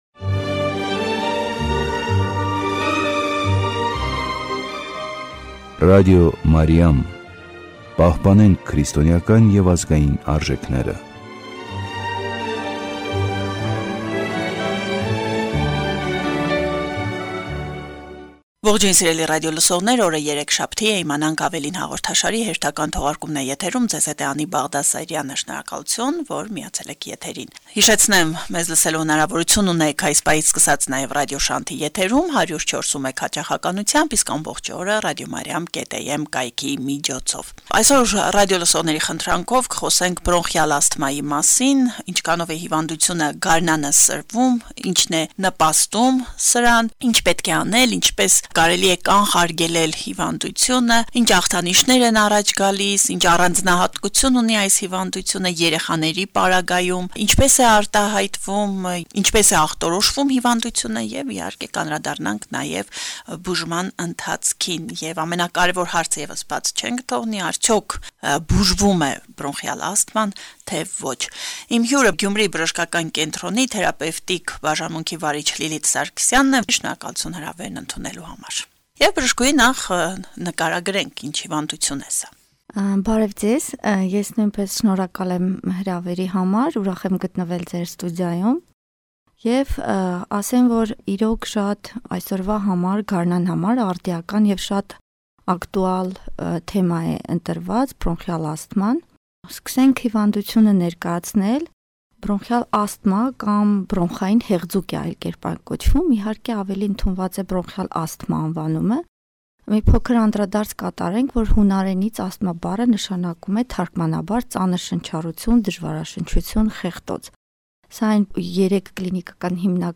Ինչպես կանխարգելել կամ բուժել հիվանդությունը. հարցազրույց